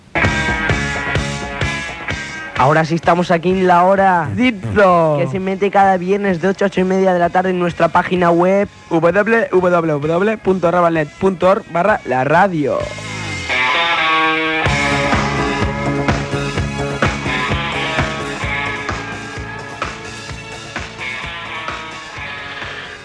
45c8d0a128a2782dc1d10160bd3069a8f43abef6.mp3 Títol Ràdio Ravalnet Emissora Ràdio Ravalnet Titularitat Tercer sector Tercer sector Barri o districte Nom programa La hora dip dop Descripció Identificació del programa i adreça web.